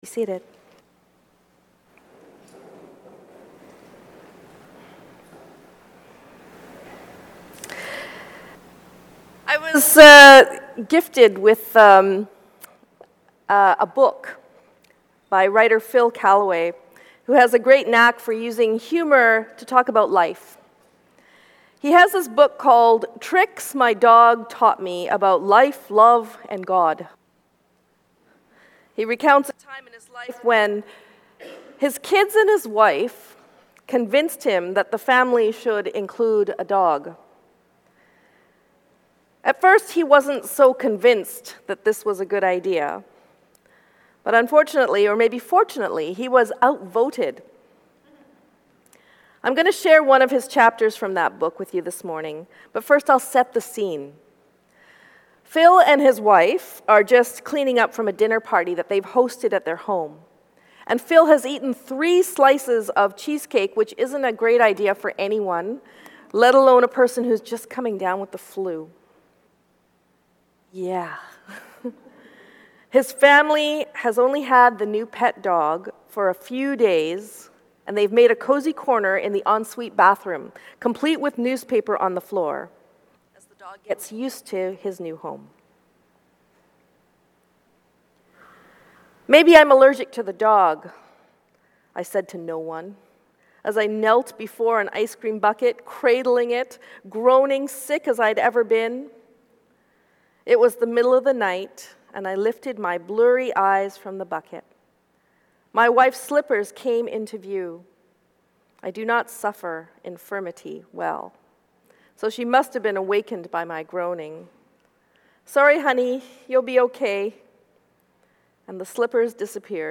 Sermon for Oct 6th